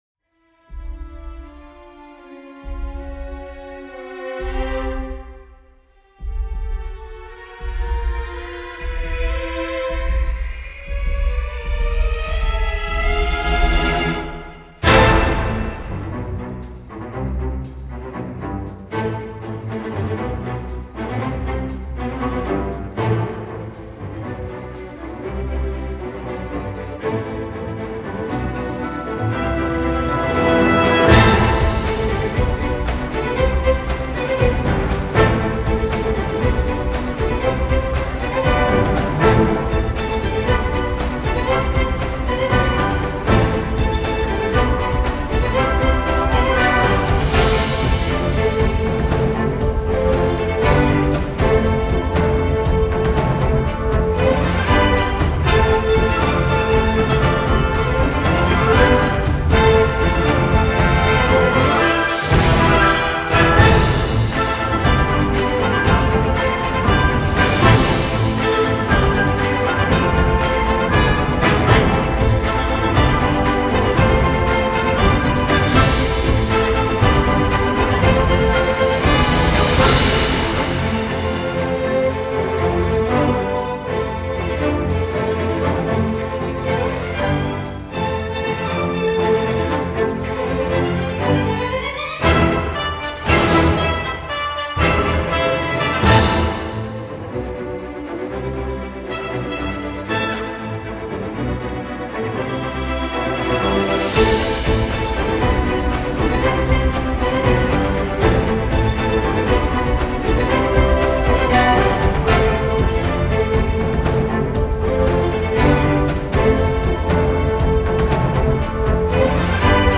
Genre: Classical
以交响乐的方式重新演绎流行金曲，恢弘霸气非常啊~